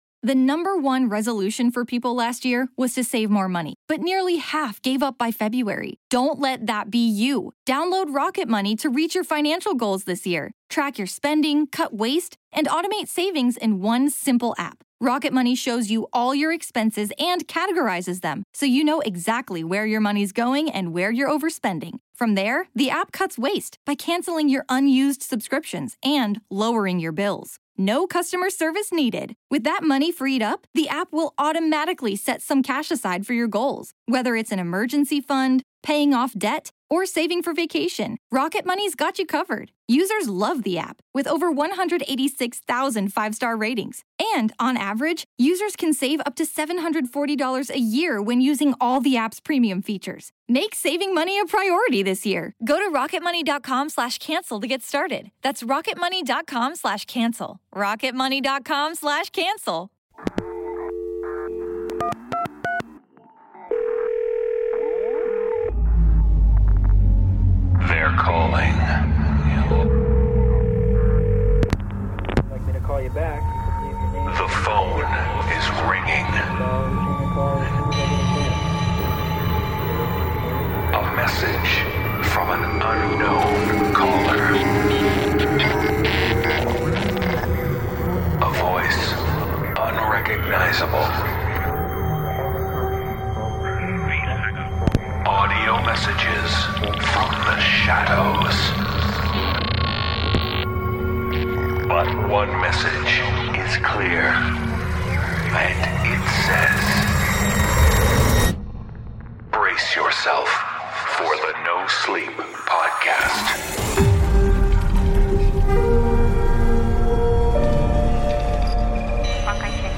The voices are calling with tales of freaky fiends.